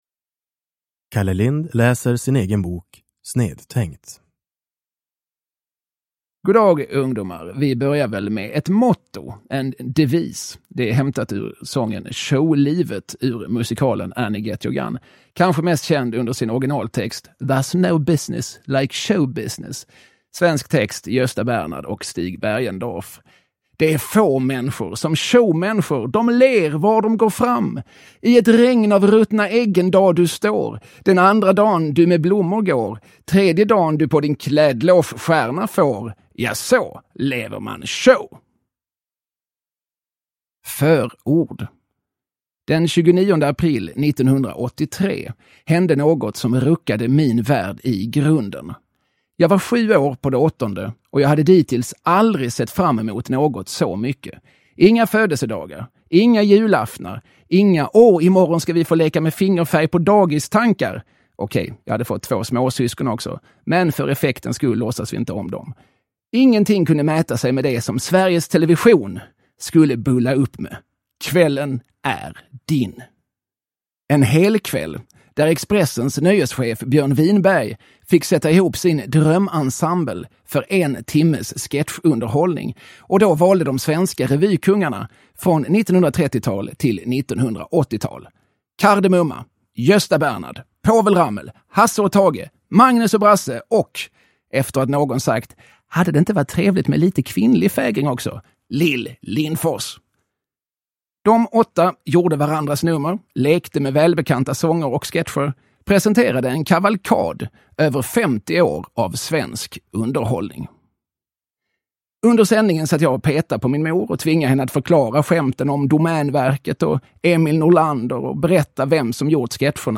Uppläsare: Kalle Lind
Ljudbok